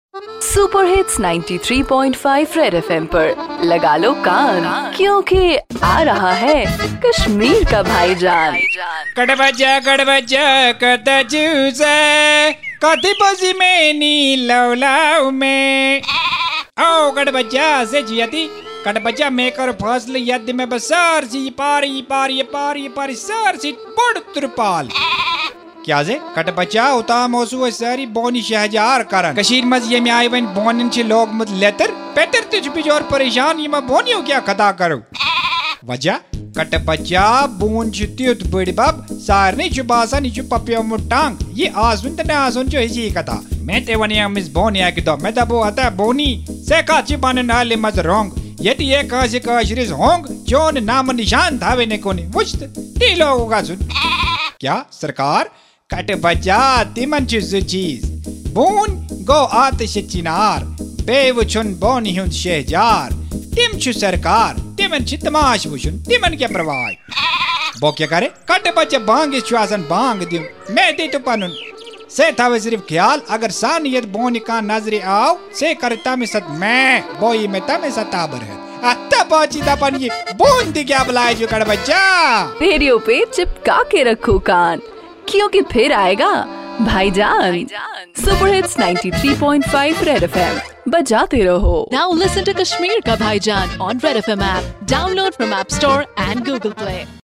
Bhaijaan the ultimate dose of comedy in Kashmir which is high on satire and humor